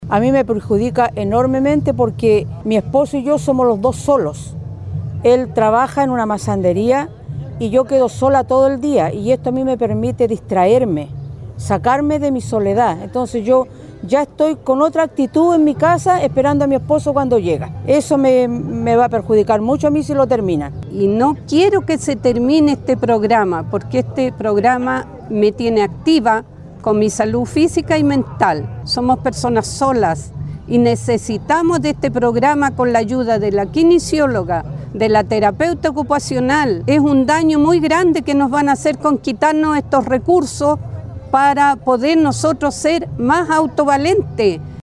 Dos-Personas-Mayores-lamentan-esta-merma-de-recursos-.mp3